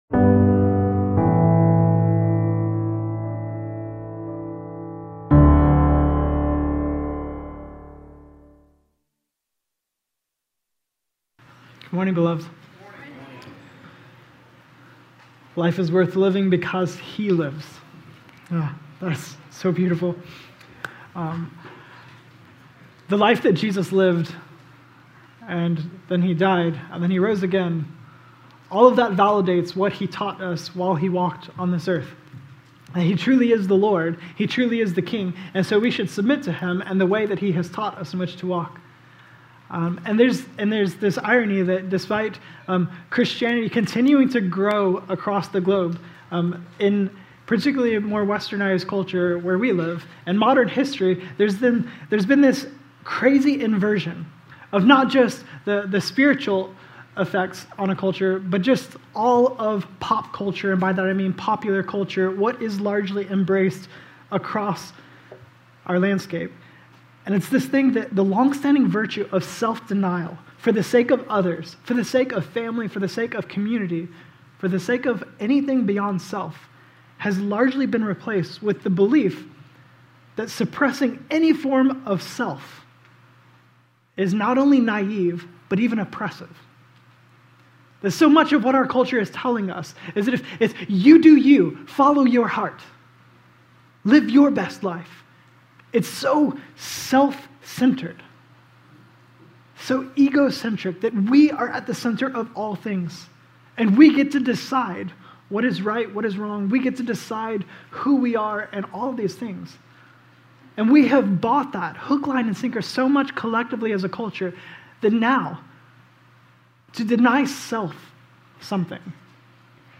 32325-Serm-a.mp3